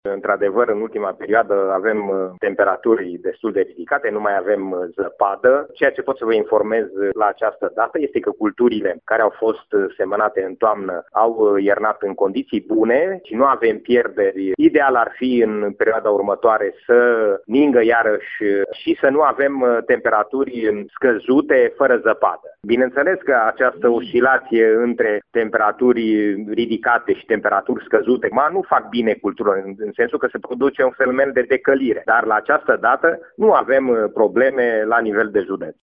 Este de preferat să ningă și să nu mai fie variații mari de temperatură, spune șeful Direcției Agricole Mureș, Liviu Timar: